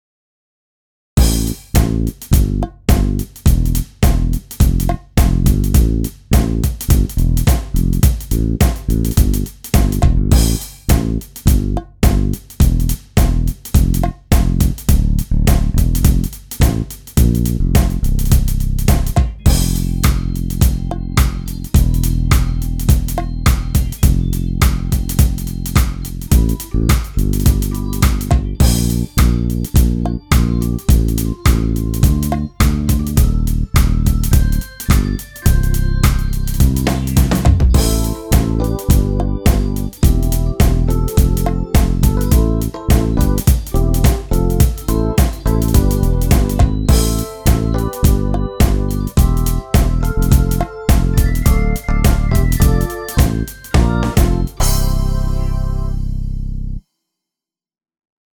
Grooviger Sound
Januarsong-Playback.mp3